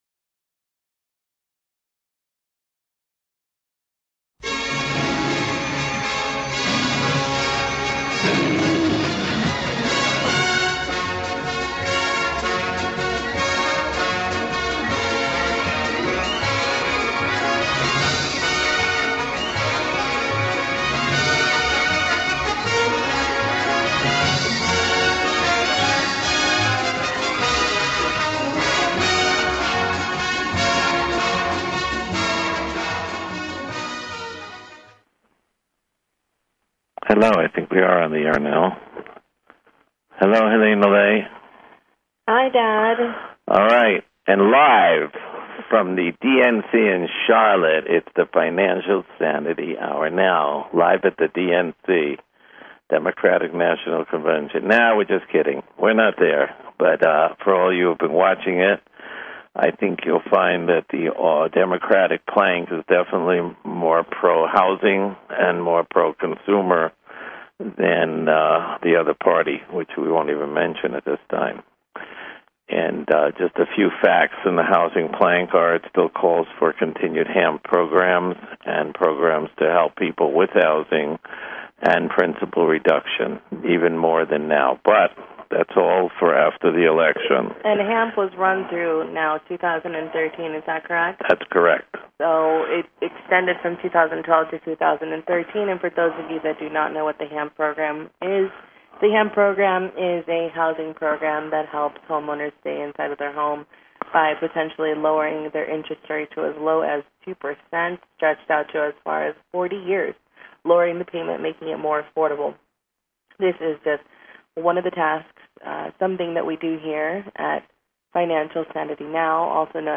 Talk Show Episode, Audio Podcast, Financial Sanity Now and Courtesy of BBS Radio on , show guests , about , categorized as